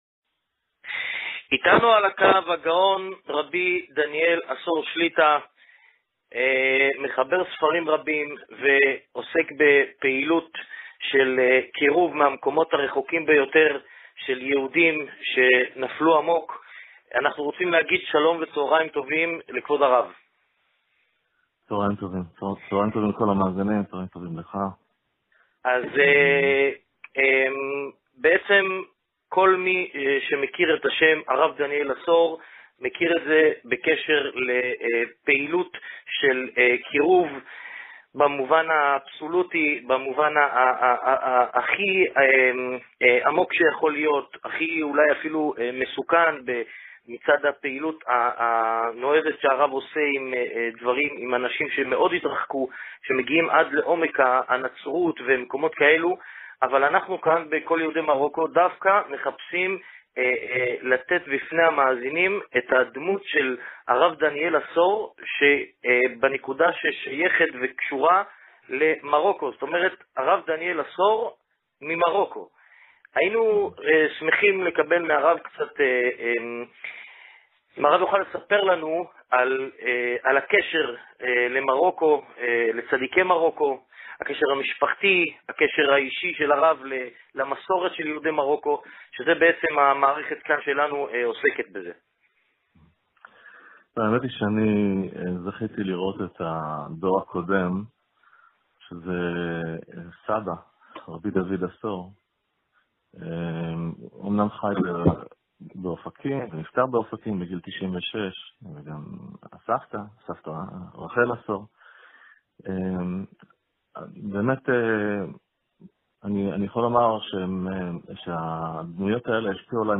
מספר על העבר המשפחתי שלו ברדיו יהודי מרוקו